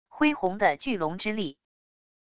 恢宏的巨龙之力wav音频